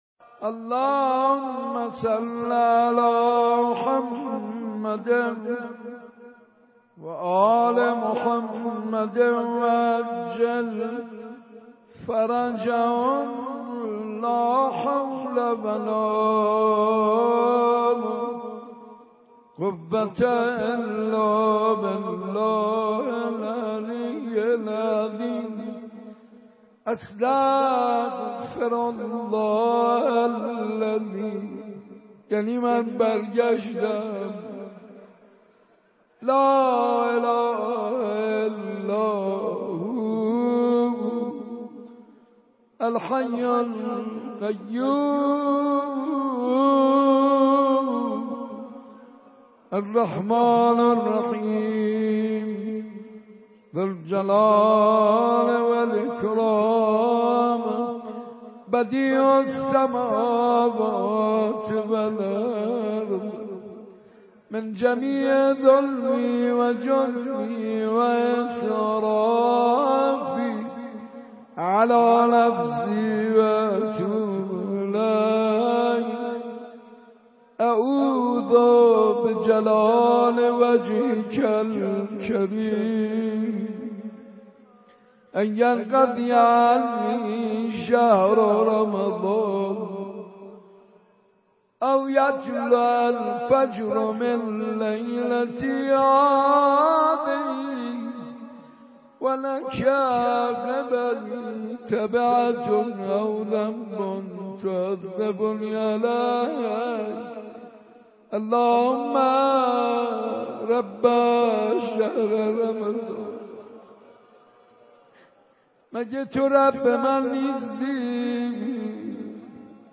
دانلود صوت مناجات خوانی حاج منصور ارضی مراسم شب دوازدهم و سیزدهم ماه مبارک رمضان۱۳۹۷ – مجله نودیها
صوت | مراسم شب دوازدهم ماه مبارک رمضان با نوای حاج منصور ارضی